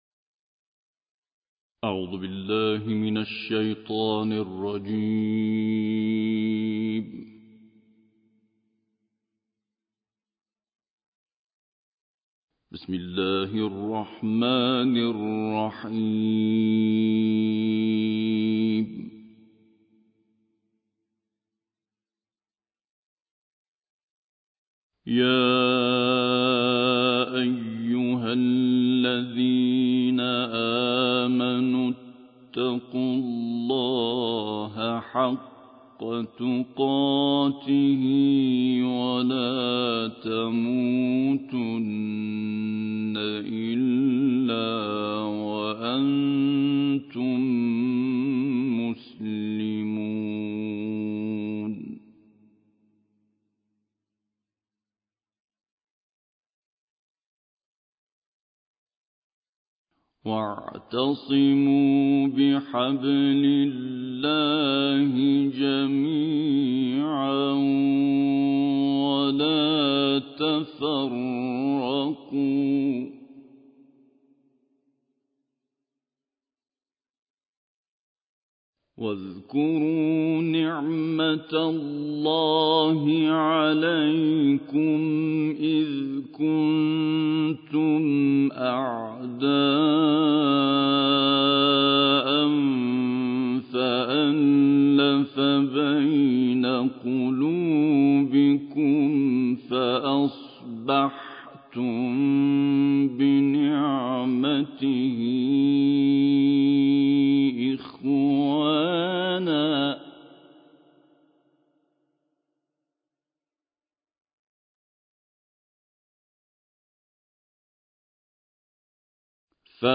دانلود قرائت سوره آل عمران آیات 102 تا 115 - استاد سعید طوسی